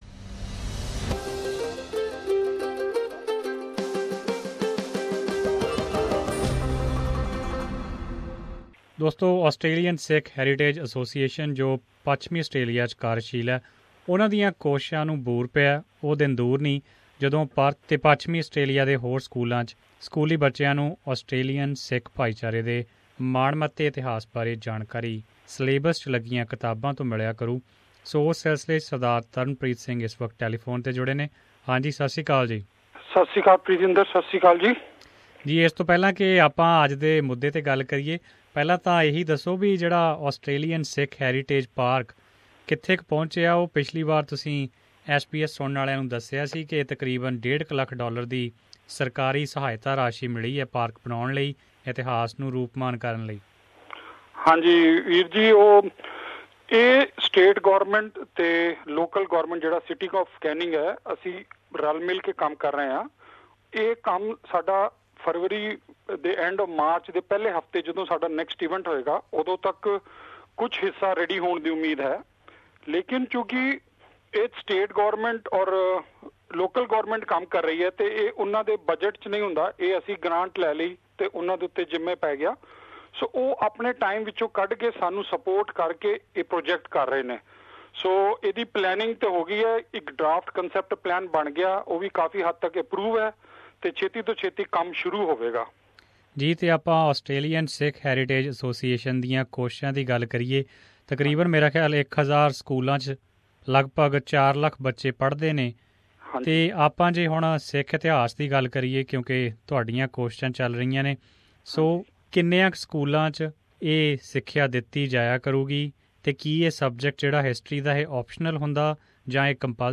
reports...